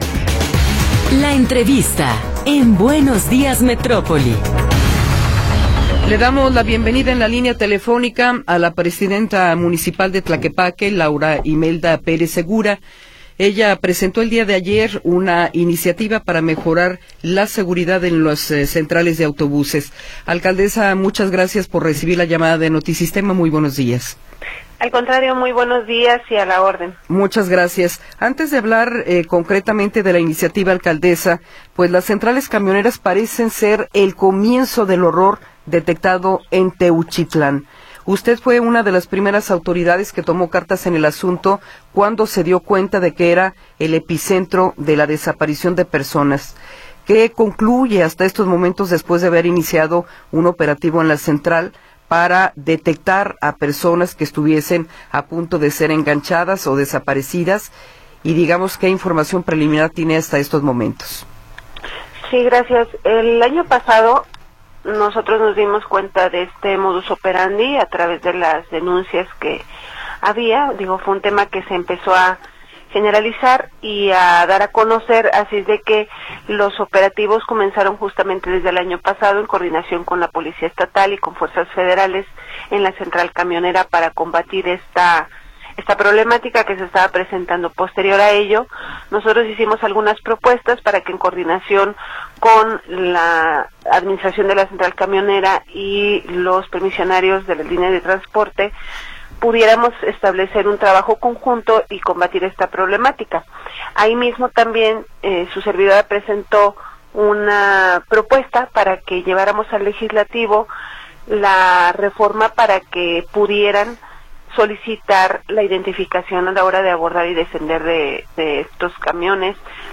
Entrevista con Laura Imelda Pérez Segura